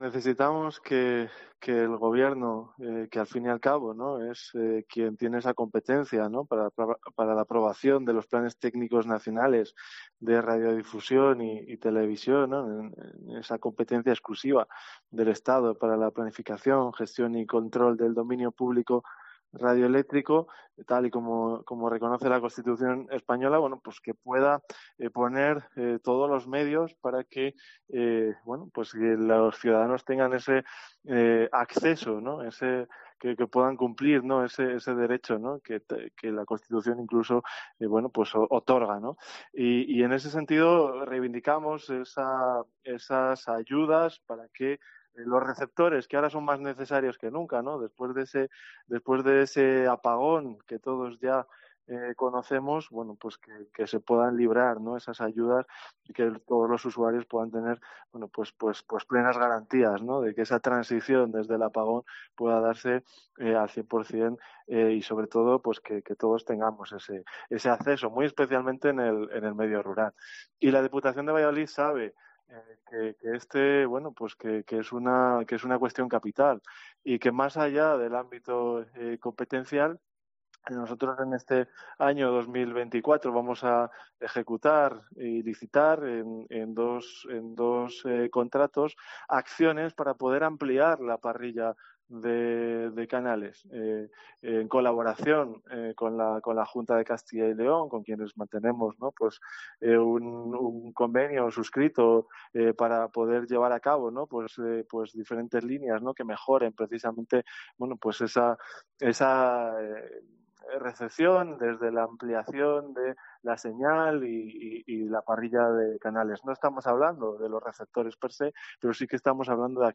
David Esteban explica en COPE Valladolid las acciones de la Diputación para mejorar la señal de TDT